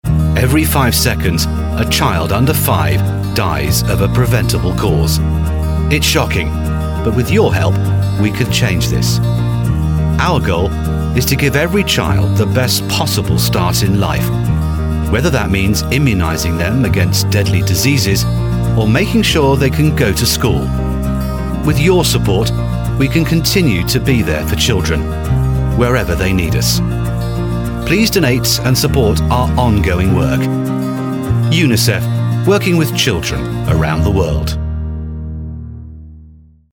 外籍男108-大气商业广告宣传片合辑
英国广播公司电台、电视台和英式英语配音 一个富有内涵、热情的英国人，英国广播公司的主播，有深度，有个性，如果需要，有很强的权威性。
拥有专业的声音工作室。
外籍108-联合国儿童基金会宣传片-深情.mp3